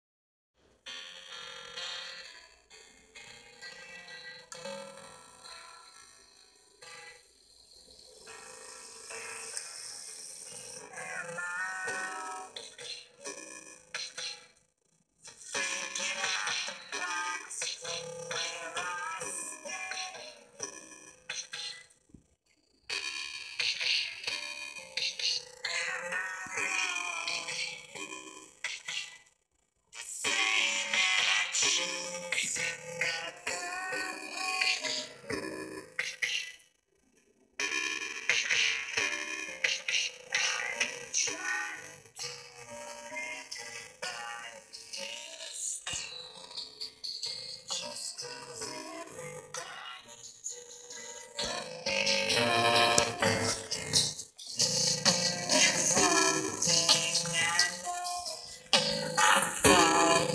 My guess: phase distortion from feeding the audio back upon itself in the mixer.